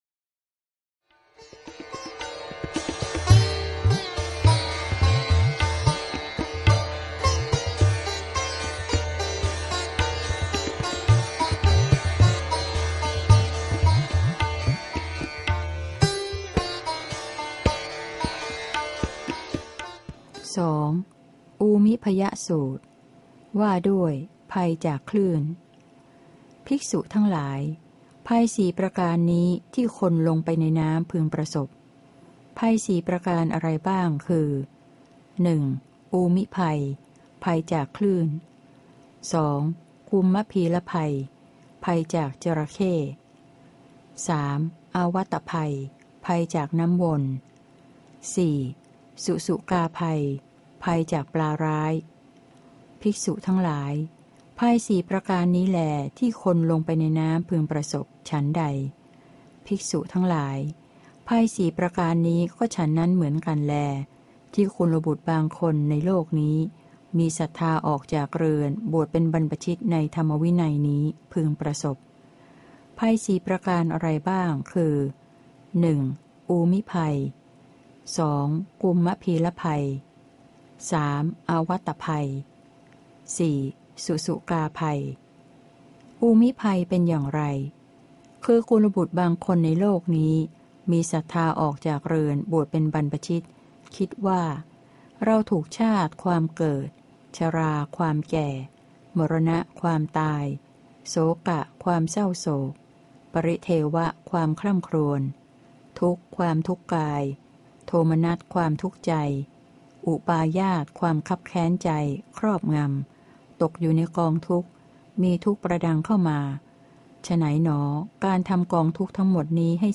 พระไตรปิฎก ภาคเสียงอ่าน ฉบับมหาจุฬาลงกรณราชวิทยาลัย - เล่มที่ ๒๑ พระสุตตันตปิฏก